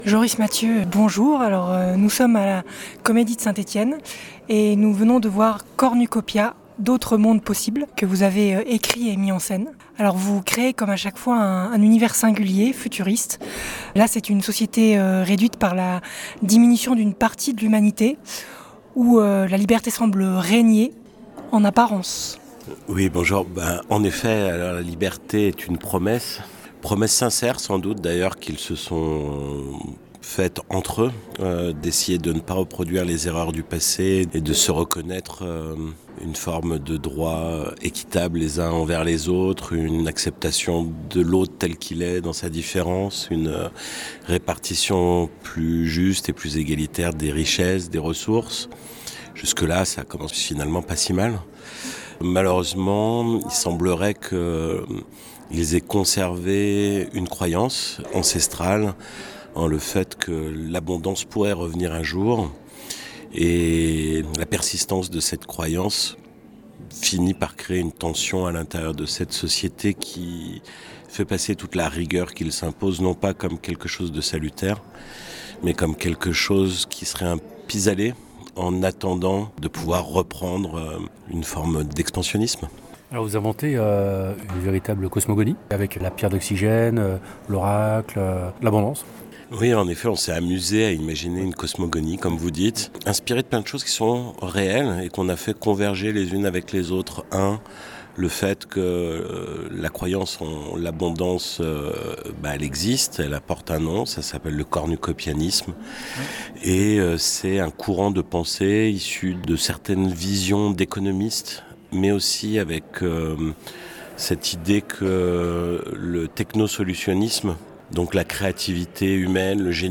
Rencontre à l’issue de la représentation